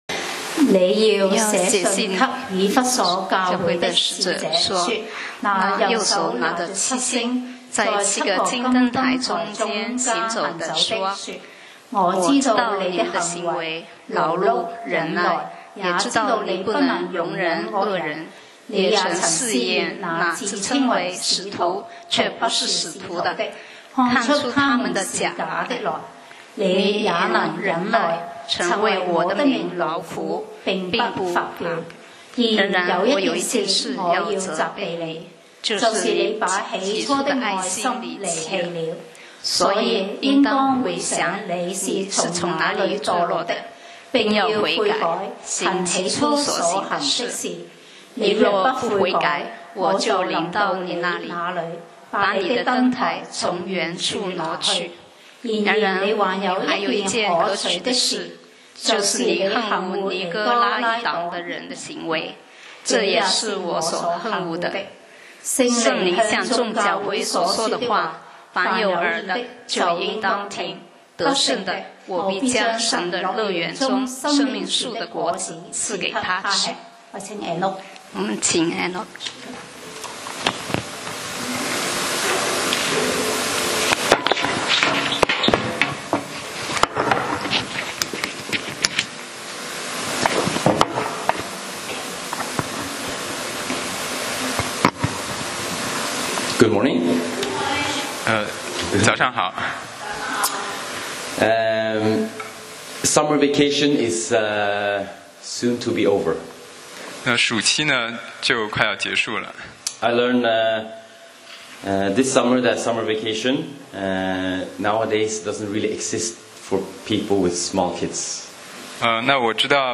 講道 Sermon 題目 Topic：起初的愛 First love 經文 Verses：啟示錄 Revelation 2:1-7. 1 你要寫信給以弗所教會的使者，說：那右手拿著七星、在七個金燈臺中間行走的，說：2我知道你的行為、勞碌、忍耐，也知道你不能容忍惡人。